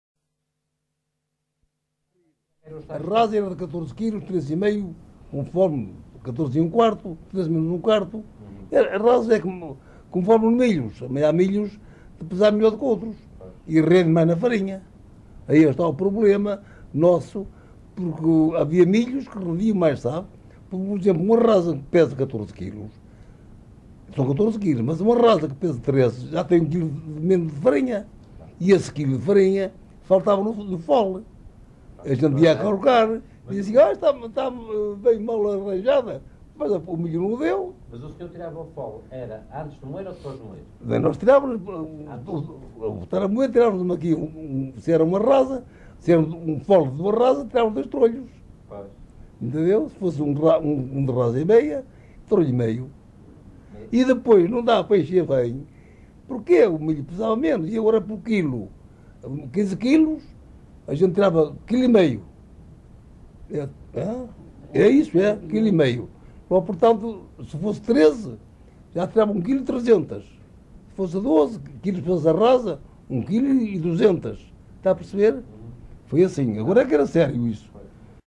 LocalidadeArcos de Valdevez (Arcos de Valdevez, Viana do Castelo)